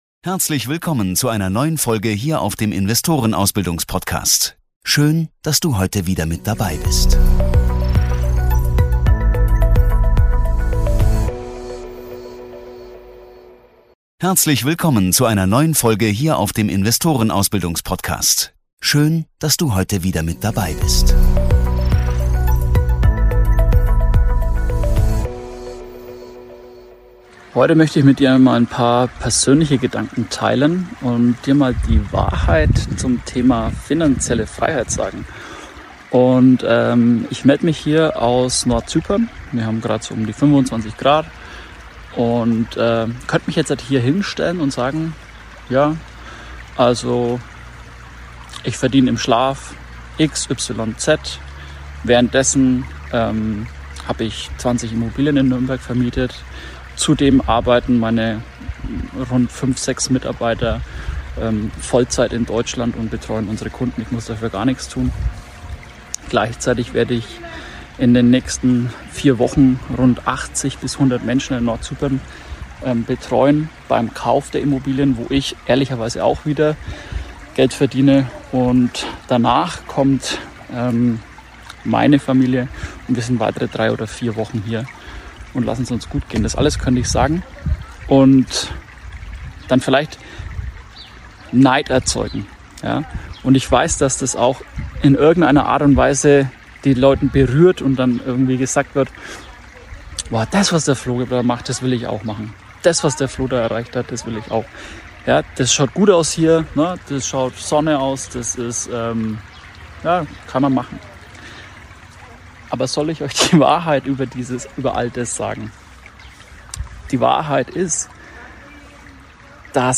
Darum schadet schneller Erfolg Dir und Deiner Entwicklung. Ich melde mich heute nicht aus meinem Büro, sondern aus Zypern. Deshalb entschuldigt bitte die Hintergrundgeräusche.